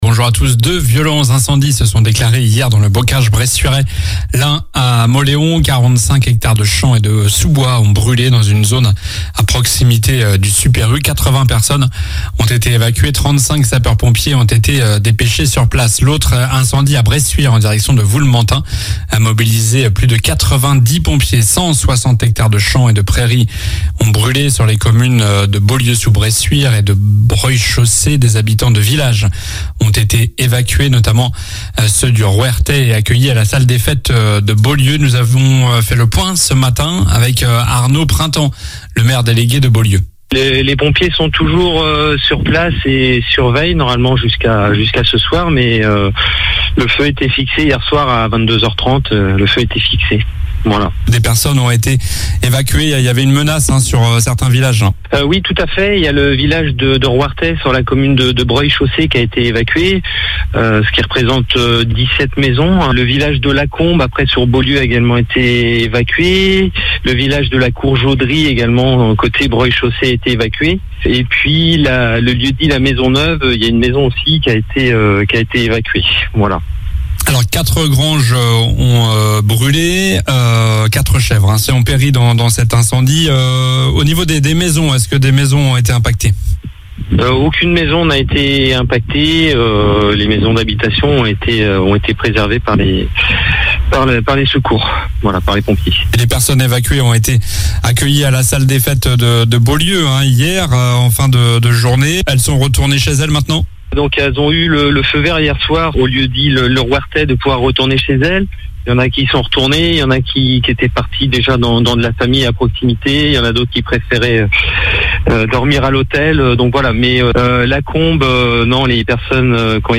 COLLINES LA RADIO : Réécoutez les flash infos et les différentes chroniques de votre radio⬦